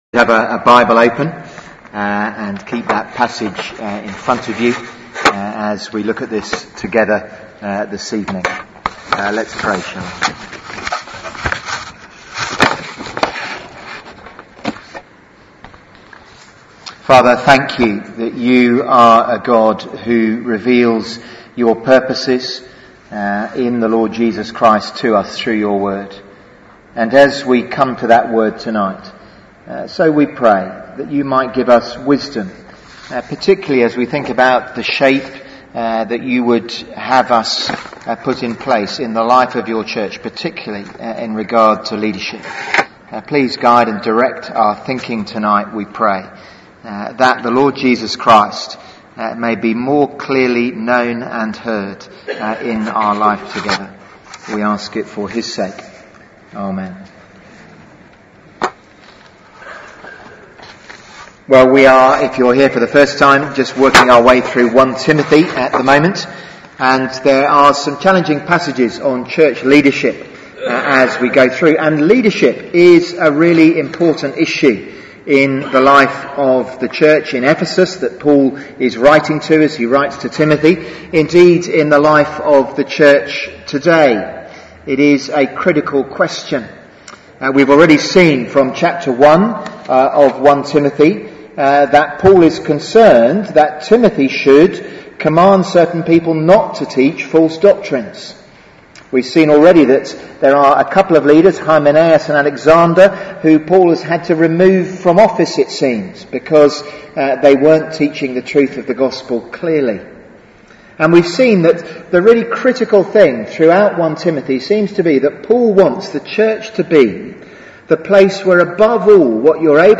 Theme: Leadership in the church Sermon